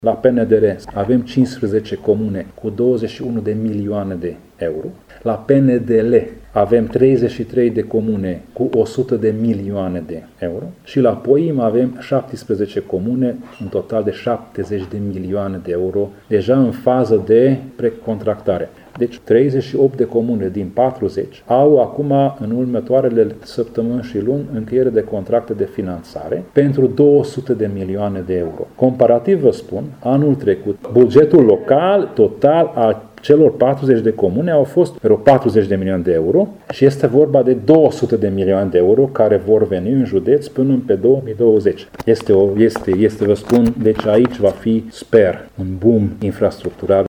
Președintele Consiliului Județean Covasna, Tamas Sandor: